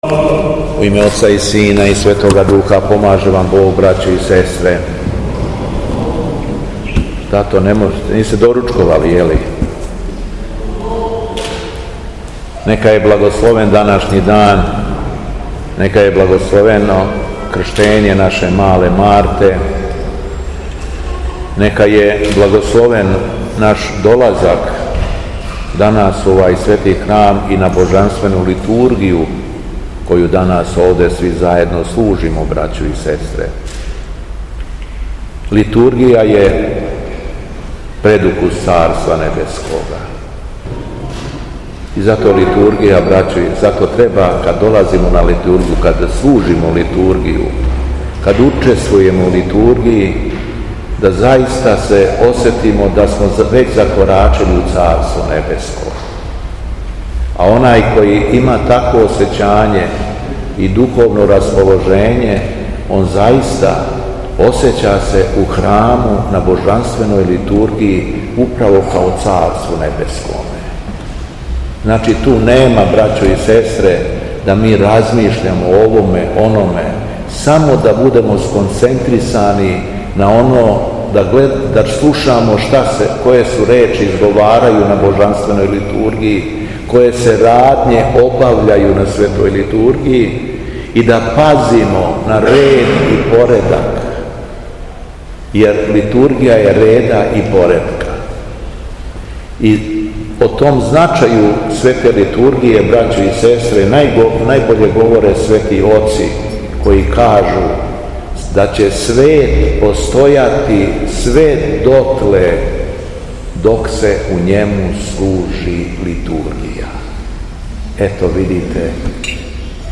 СВЕТА ЛИТУРГИЈА У ХРАМУ ПРЕОБРАЖЕЊА ГОСПОДЊЕГ У СМЕДЕРЕВСКОЈ ПАЛАНЦИ - Епархија Шумадијска
Беседа Његовог Преосвештенства Епископа шумадијског г. Јована
Након прочитаног јеванђелског зачала верном народу обратио се Преосвећени Владика речима: